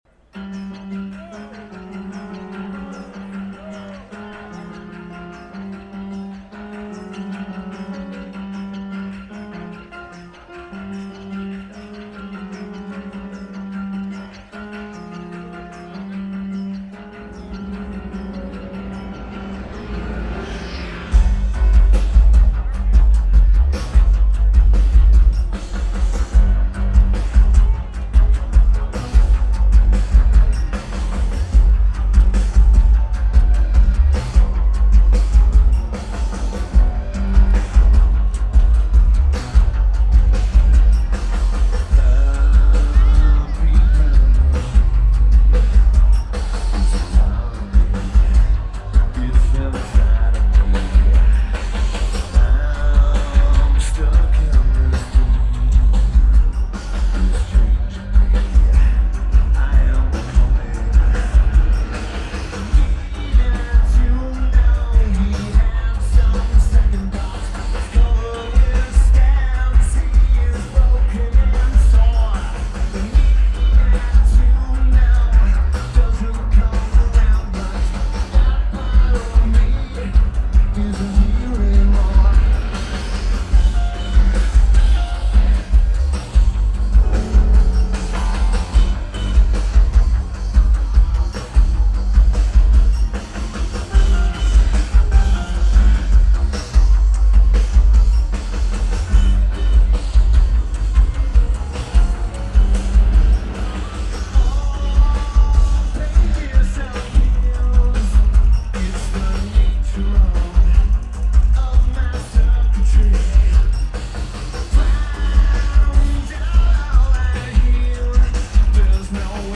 Louder Than Life Festival
Lineage: Audio - AUD (AT853 (4.7k mod) + Sony PCM-A10)